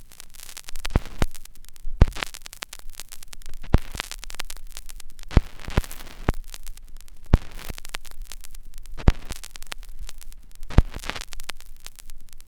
DAMAGE    -L.wav